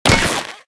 Minecraft Version Minecraft Version 25w18a Latest Release | Latest Snapshot 25w18a / assets / minecraft / sounds / item / armor / crack_wolf3.ogg Compare With Compare With Latest Release | Latest Snapshot
crack_wolf3.ogg